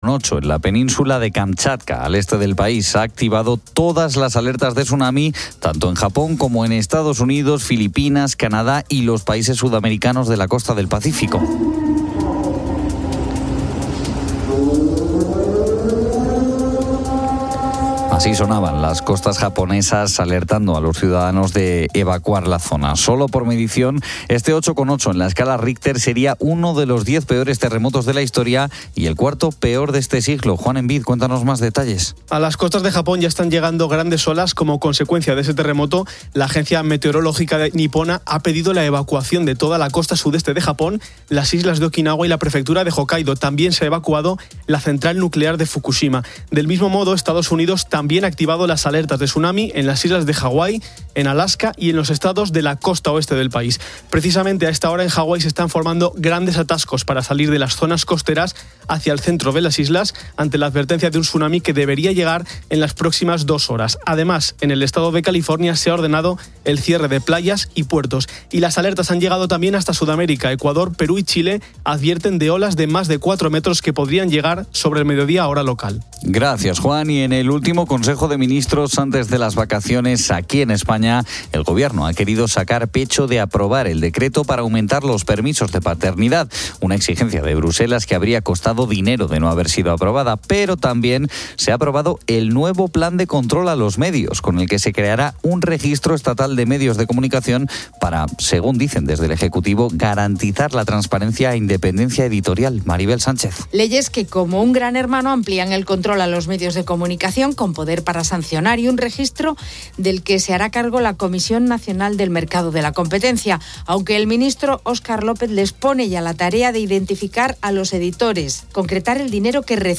Así sonaban las costas japonesas alertando a los ciudadanos de evacuar la zona.